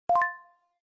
Alert_Exclaim.wma